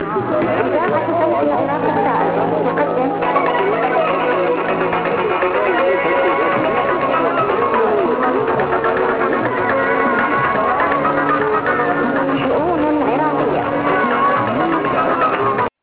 Voice of Rebellious Iraq station identification ), Idha'at sawt al-Iraq al-Tha'r, is another station operated by SCIRI.